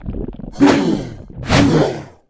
attack_act_2.wav